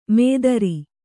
♪ mēdari